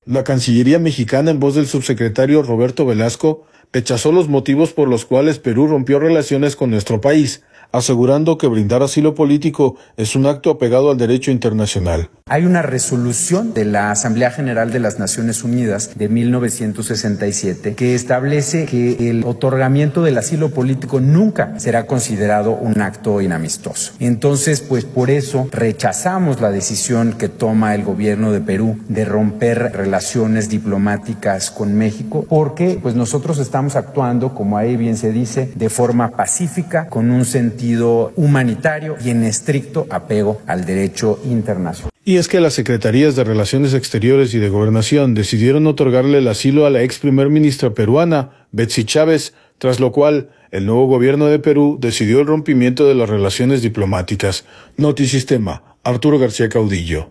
La Cancillería mexicana en voz del subsecretario Roberto Velasco, rechazó los motivos por los cuales Perú rompió relaciones con nuestro país, asegurando que brindar asilo político es un acto apegado al derecho internacional.